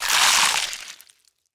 Bite Flesh Bloody Crunch 2
Category: Sound FX   Right: Both Personal and Commercial
Tags: Call of Cthulhu Horror Ambience Sound effects